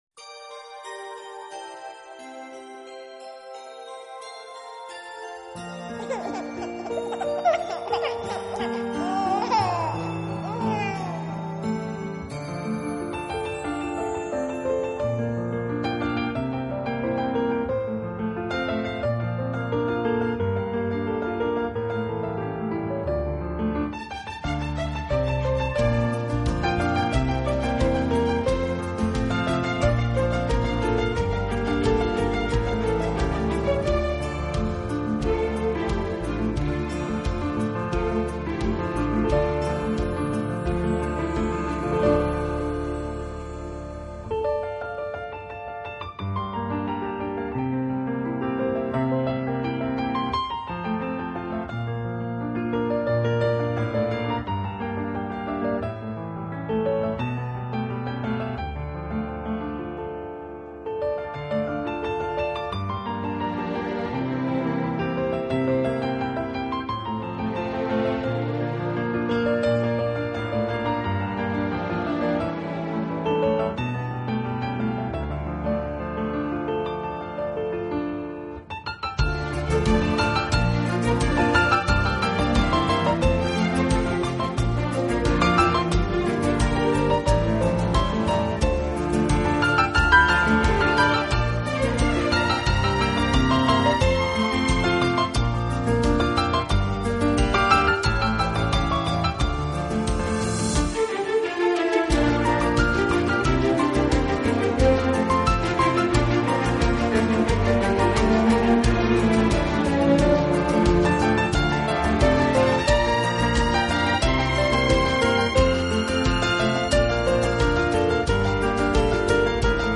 拉丁钢琴